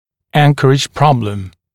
[‘æŋkərɪʤ ‘prɔbləm][‘энкэридж ‘проблэм]проблема анкеровки, сложность при анкеровке